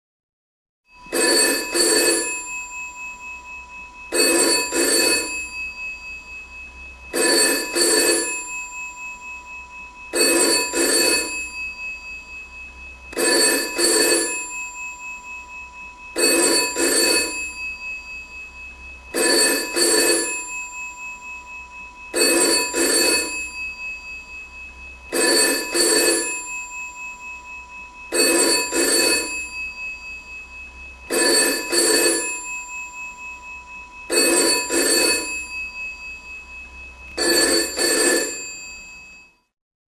British phone ringing
The sound of a GPO phone ringing in the hallway.
phone_ringing_long.mp3